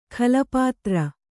♪ khala pātra